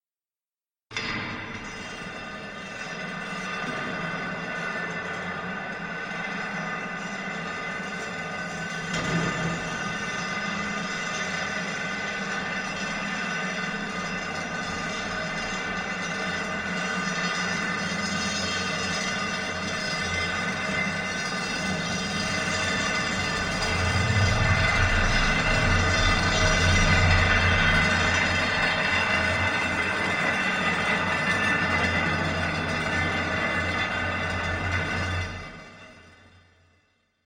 金属が床を引きずる不規則な音色が、不安感を高める。